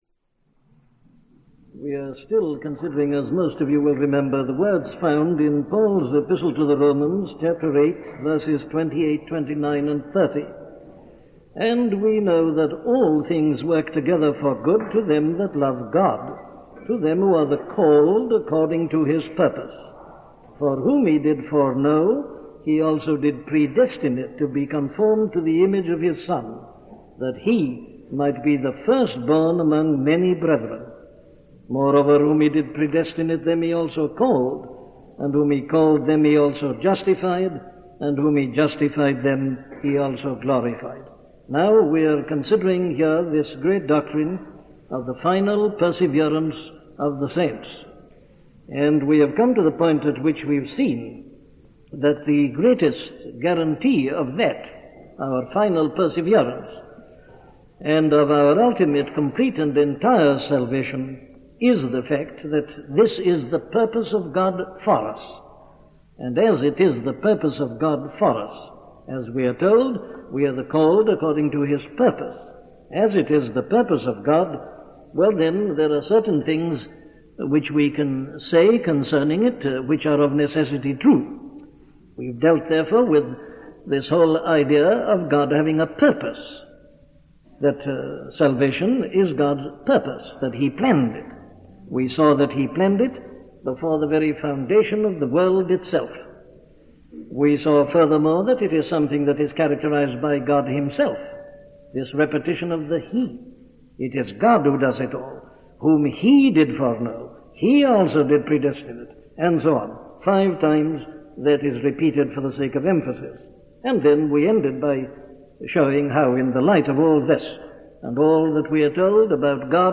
Conformed to Christ - a sermon from Dr. Martyn Lloyd Jones
Listen to the sermon on Romans 8:28-30 'Conformed to Christ' by Dr. Martyn Lloyd-Jones